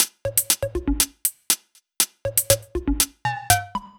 Index of /musicradar/french-house-chillout-samples/120bpm/Beats
FHC_BeatD_120-03_Tops.wav